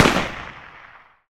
explosionTiny2.ogg